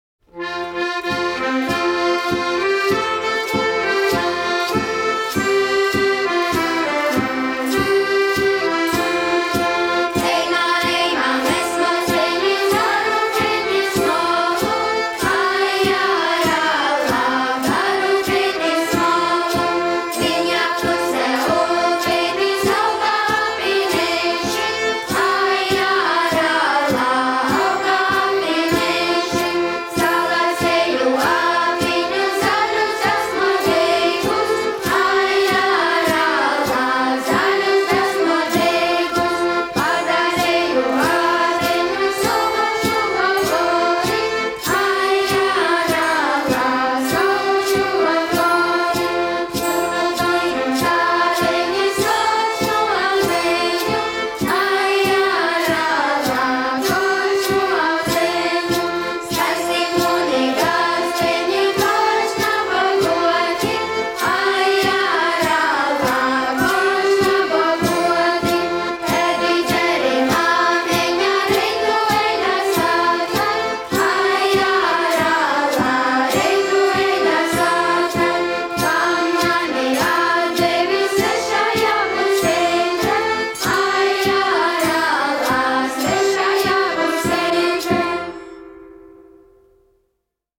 Ceiruļeits (grupa), izpildītājs
Tautas mūzika
Dziesmas
Latvijas Radio